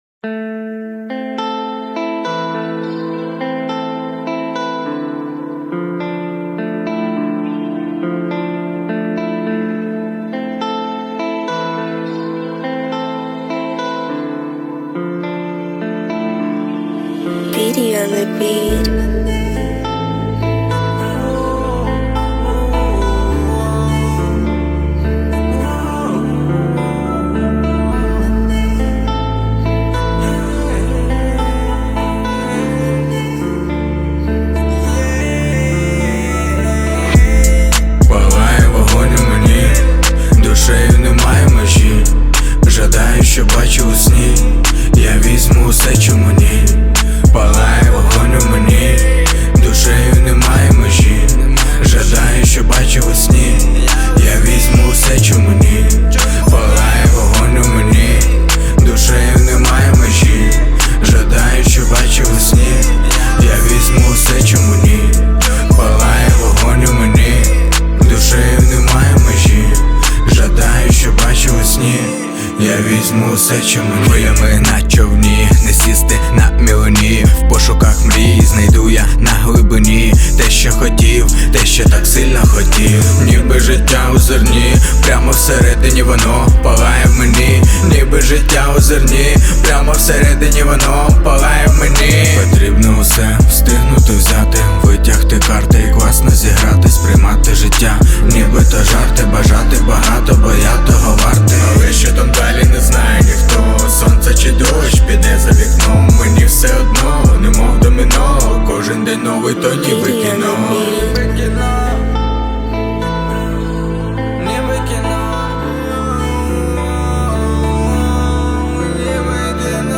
• Жанр: Rap, Hip-Hop